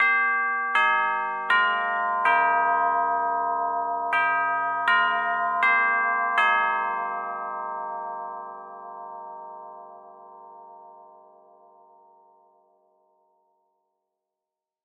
Звук курантов на часах